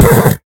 horse_hit4.ogg